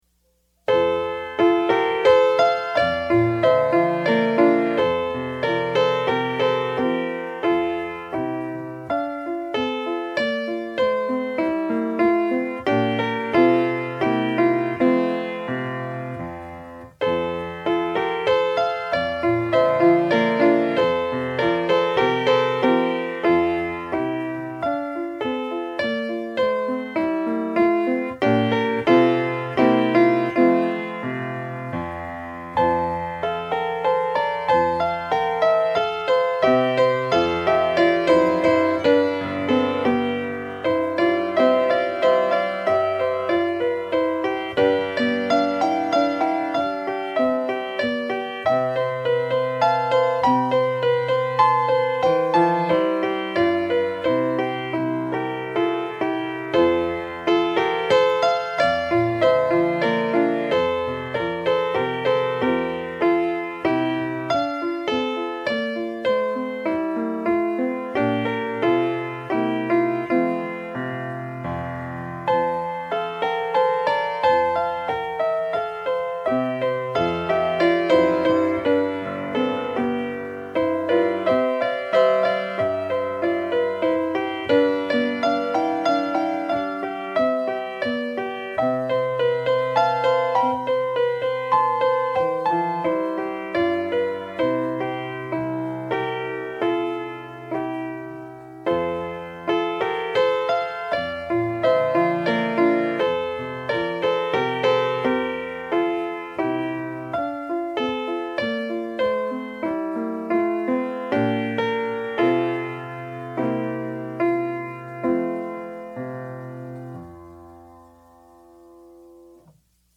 DIGITAL SHEET MUSIC - PIANO SOLO
Sacred Music, Piano Solo